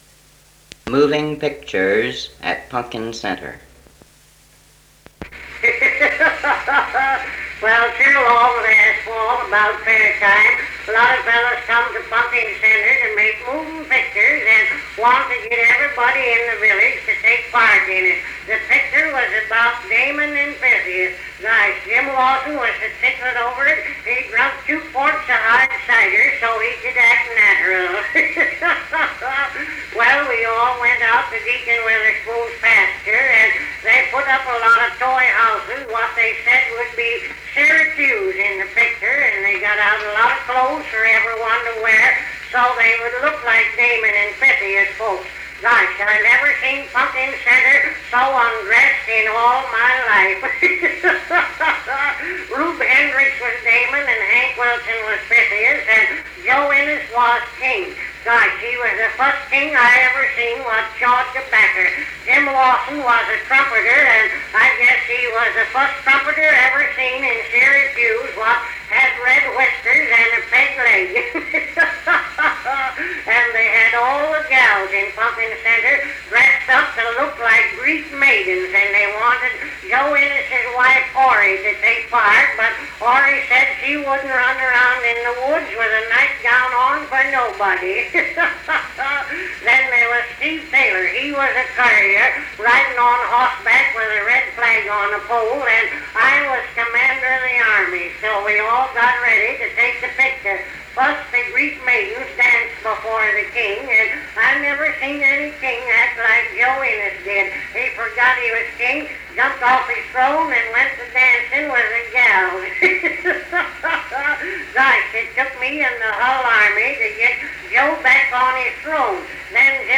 Cal Stewart's comedy routine, Moving pictures at Punkin Center.